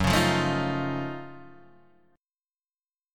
F#mM13 chord {2 4 3 2 4 1} chord